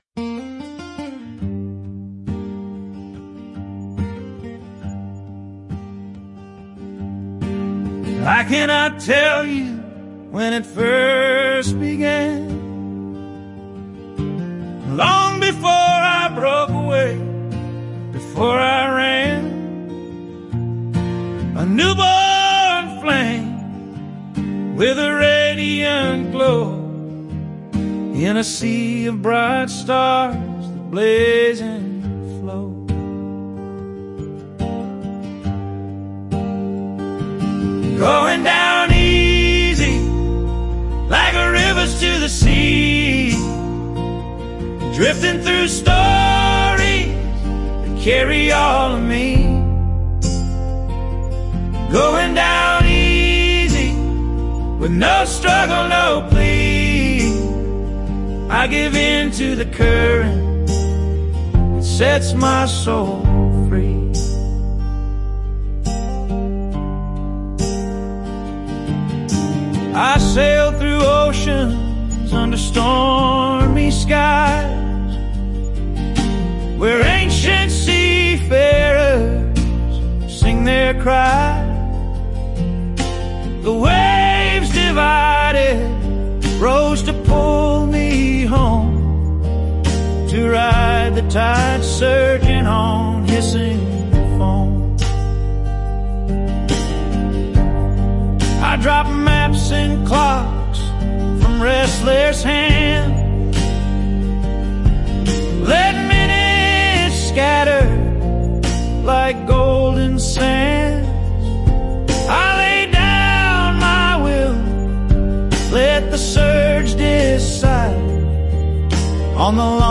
Enjoyed everything about this song the words flowed perfectly, and the musical interpretation was great.